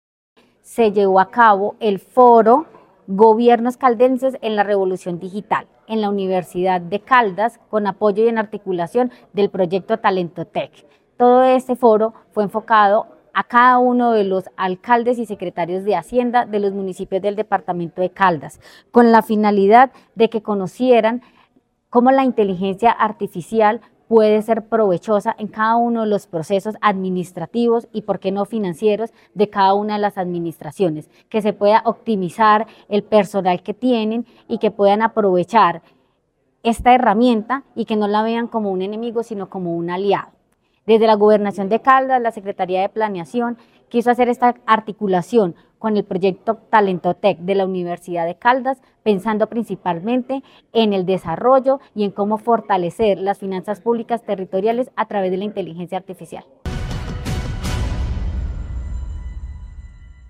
La Secretaría de Planeación de Caldas, en articulación con la Universidad de Caldas y el proyecto Talento Tech, lideró el Foro Departamental “Gobiernos Caldenses en la Revolución Digital”, un espacio orientado a analizar los retos y oportunidades que ofrece la inteligencia artificial (IA) para el desarrollo territorial.